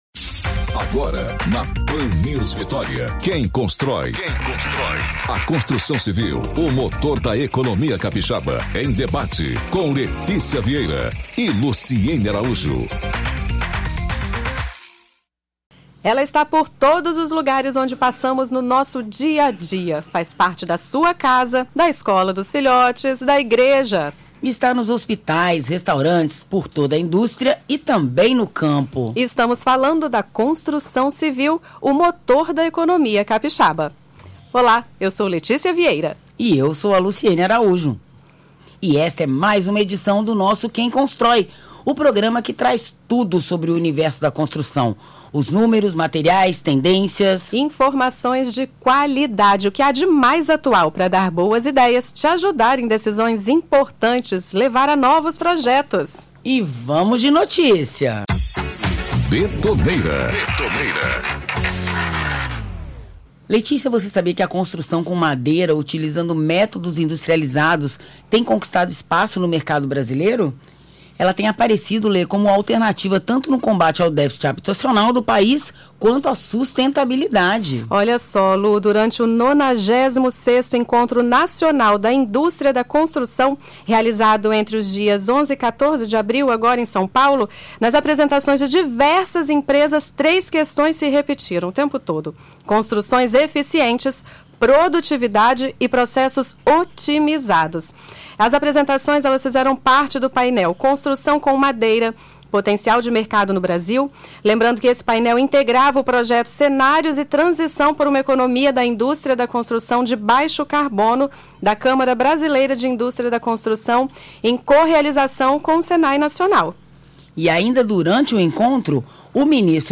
Na tarde desta quarta-feira (24) foi ao ar a segunda edição do programa Quem Cosntrói, veiculado na Pan News, trazendo o tema para debate.